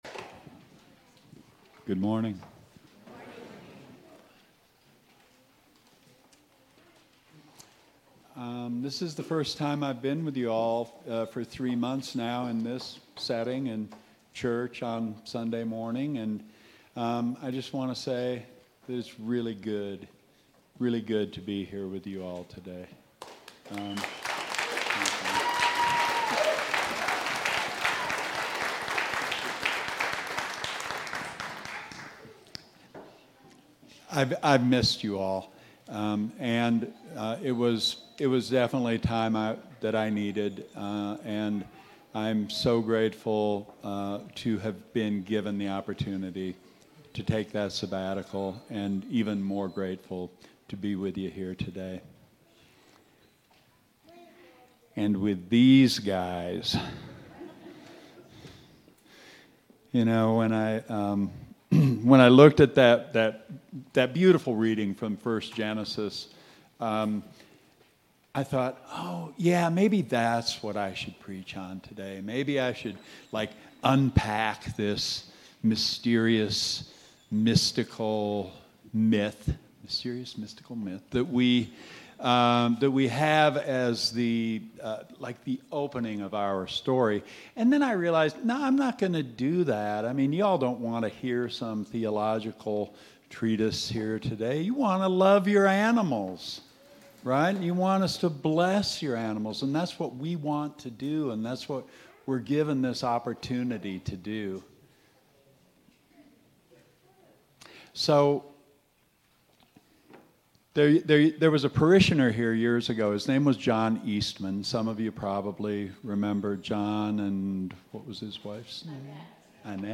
Blessing of the Animals Service
Sermons